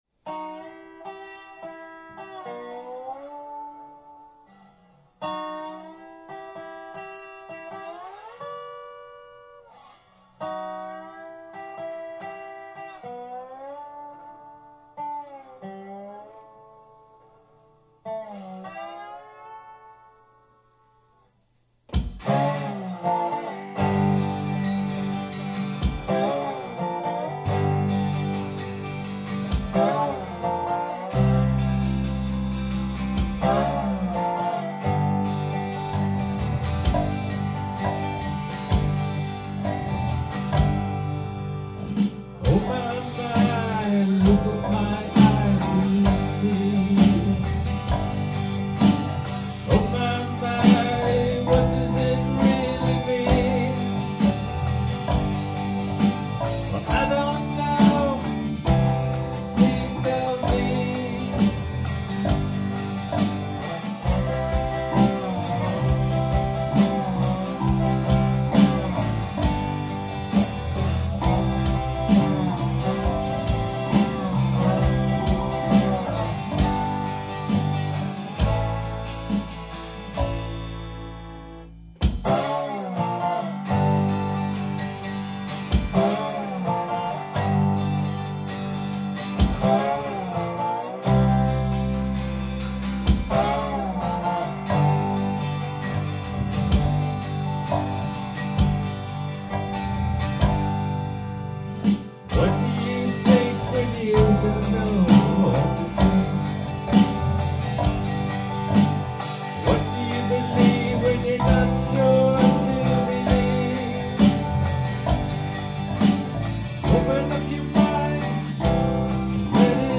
Dobro and vocals
Acoustic guitar
Bass
Percussion